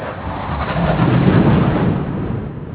JET PLANE.wav